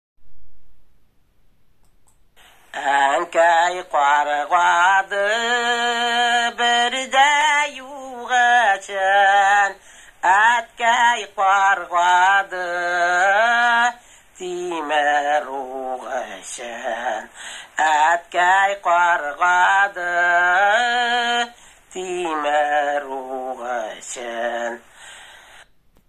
ПЕСЕННАЯ ТРАДИЦИЯ ОБСКИХ ЧАТОВ ИЗ НОВОСИБИРСКОЙ ОБЛАСТИ ВОШЛА В ПРОЕКТ «АНТОЛОГИЯ НАРОДНОЙ КУЛЬТУРЫ»